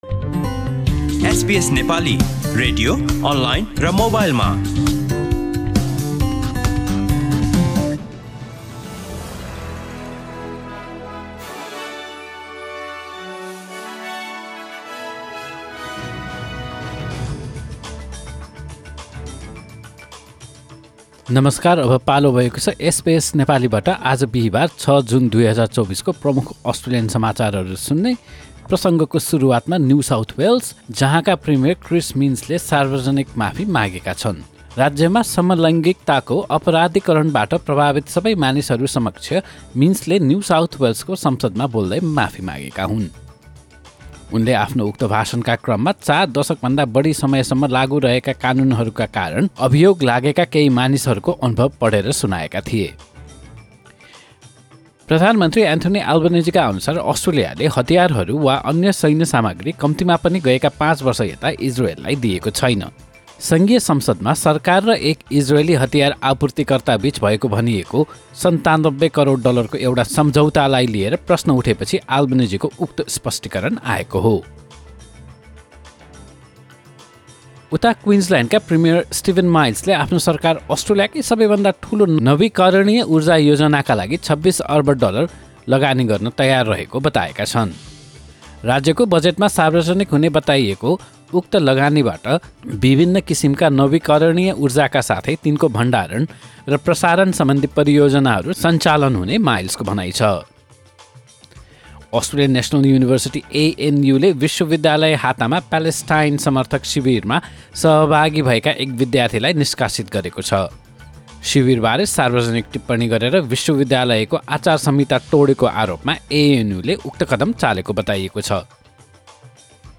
Listen to the latest bitesize top news from Australia in Nepali.